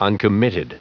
Prononciation du mot uncommitted en anglais (fichier audio)